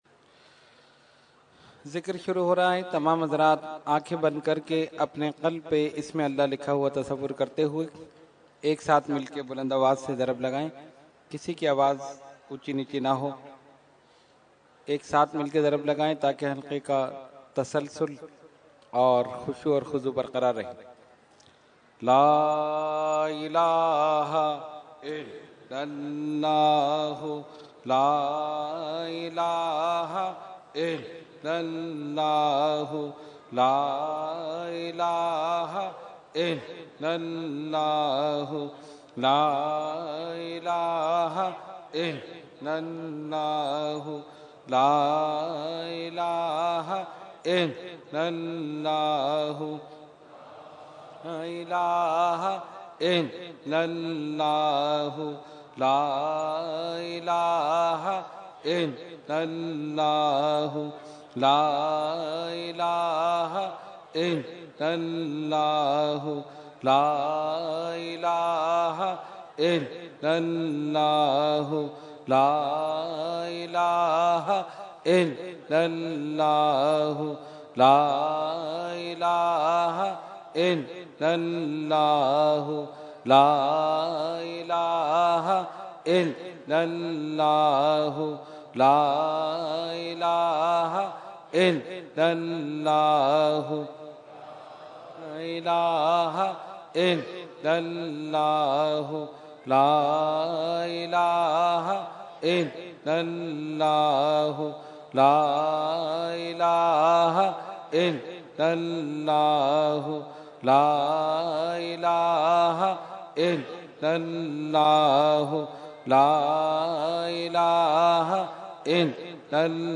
Zikar URS e Qutb e Rabbani 2015 Day3 – Dargah Alia Ashrafia Karachi Pakistan